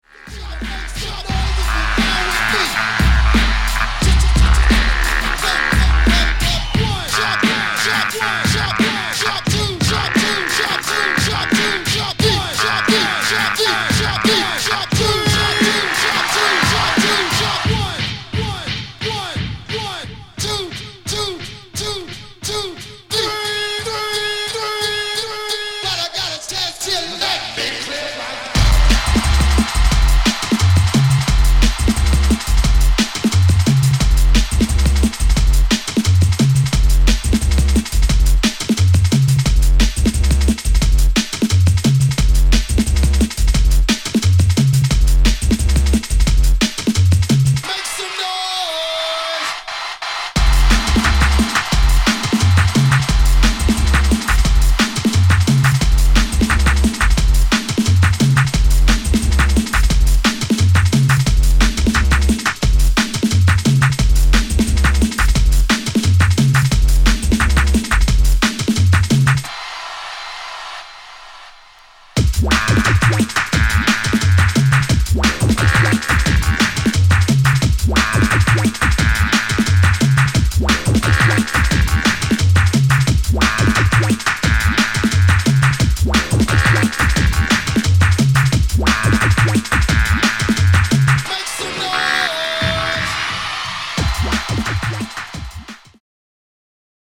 Jungle/Drum n Bass